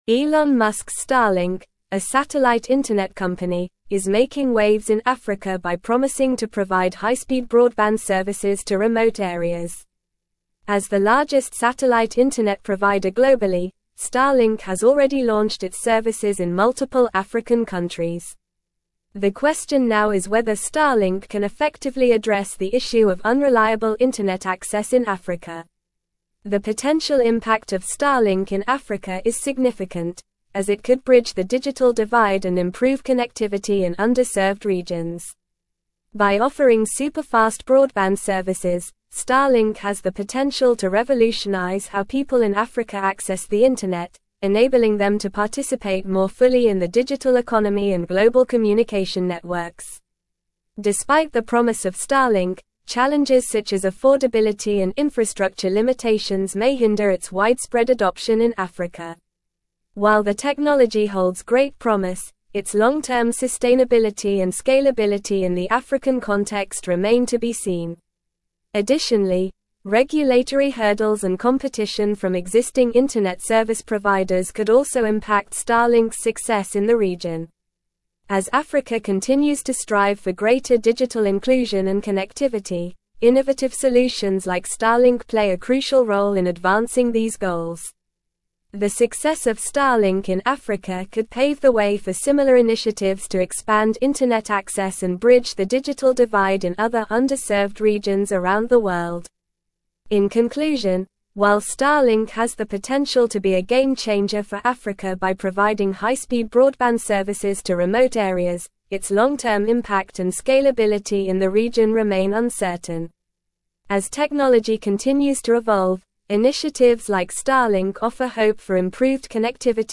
Normal
English-Newsroom-Advanced-NORMAL-Reading-Starlink-Revolutionizing-African-Connectivity-with-Satellite-Internet.mp3